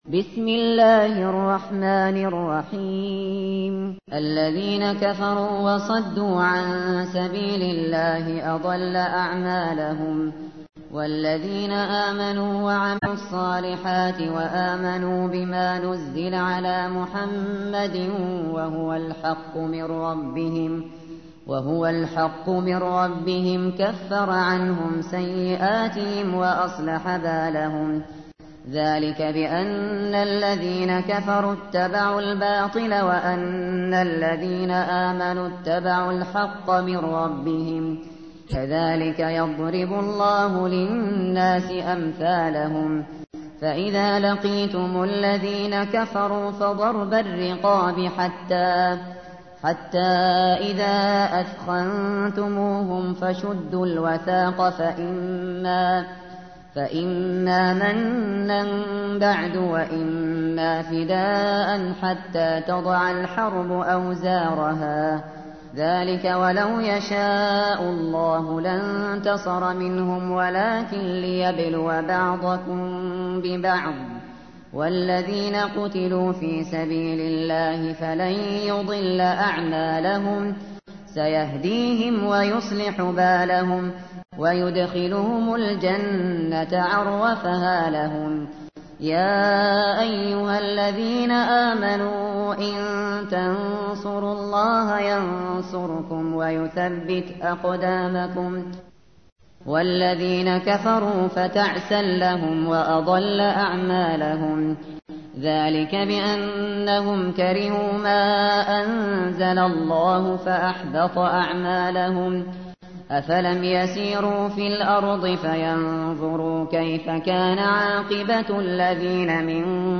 تحميل : 47. سورة محمد / القارئ الشاطري / القرآن الكريم / موقع يا حسين